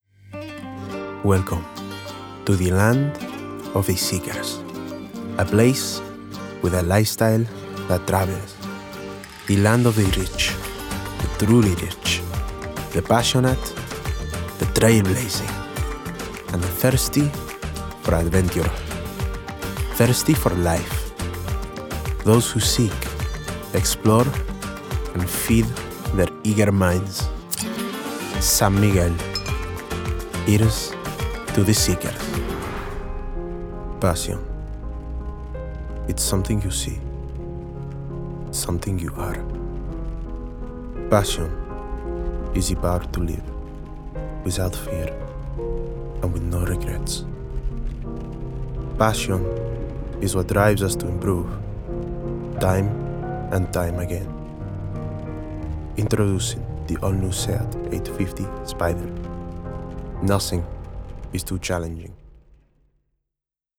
Spanish Accent Reel
Commercial, Cool, Smooth, Confident